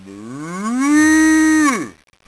cow.wav